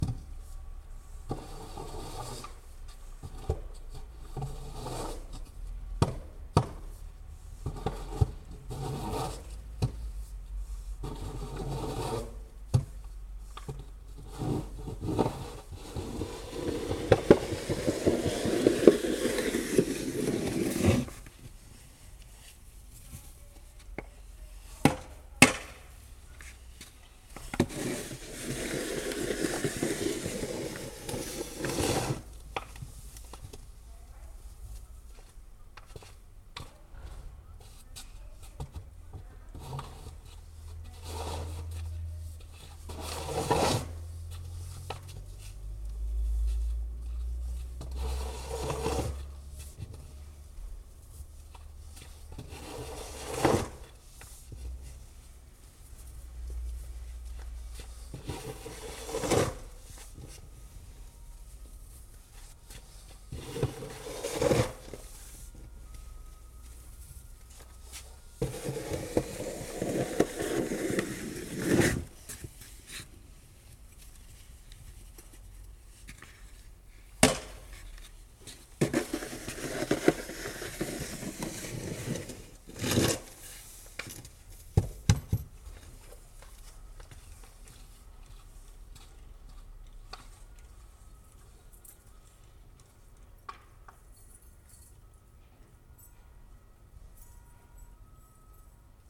Oh yeah, some snow moving right there.